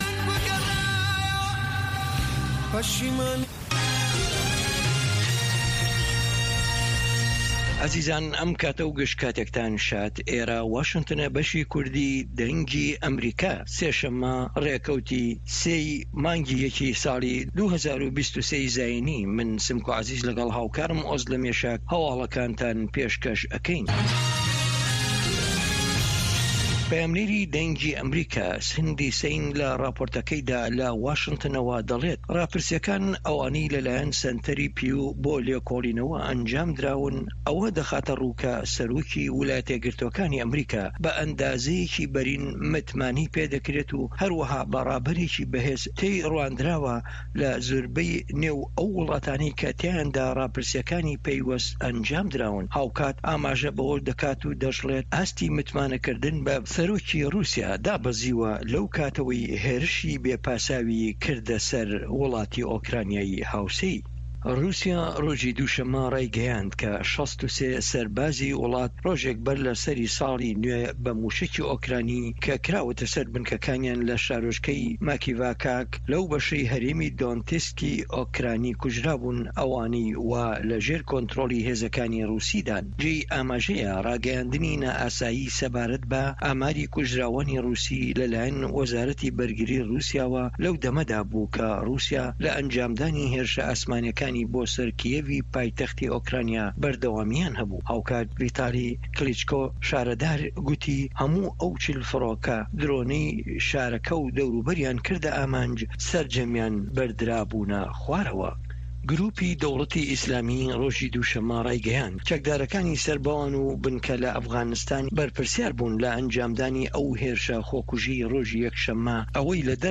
هه‌واڵه‌کان، ڕاپـۆرت، وتووێژ